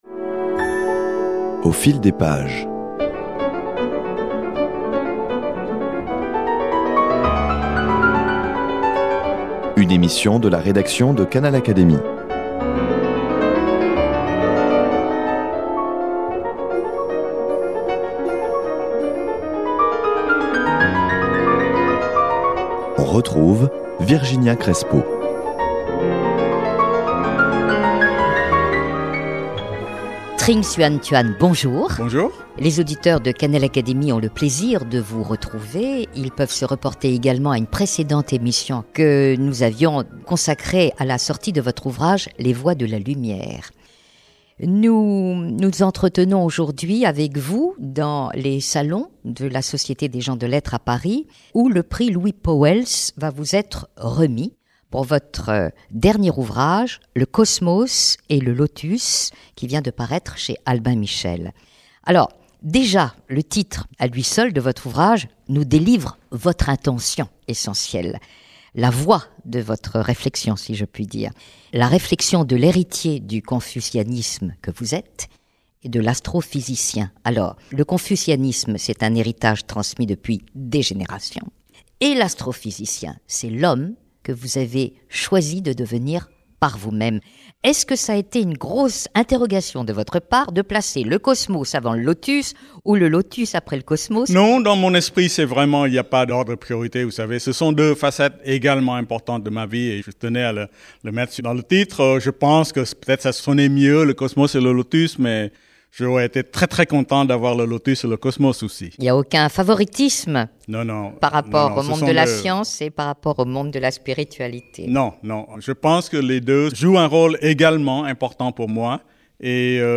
Puis elle l’a reçu dans le studio de Canal Académie.